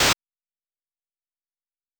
Sword.wav